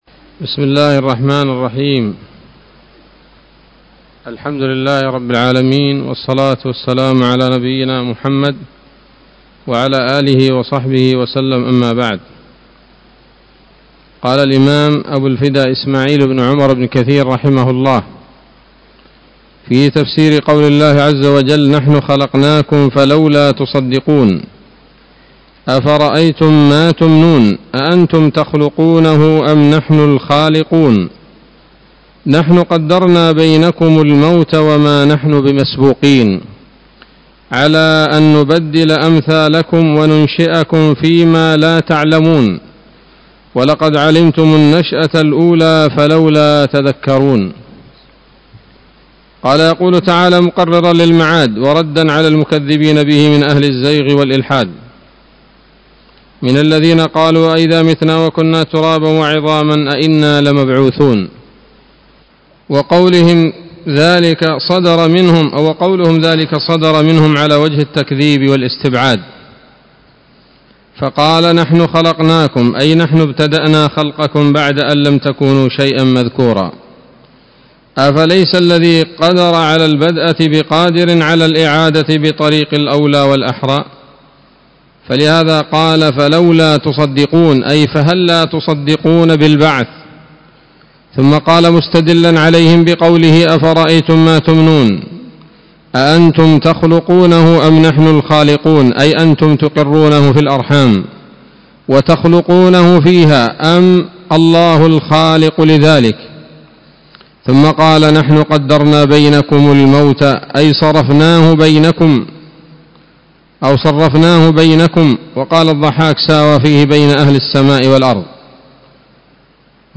الدرس الثاني عشر من سورة الواقعة من تفسير ابن كثير رحمه الله تعالى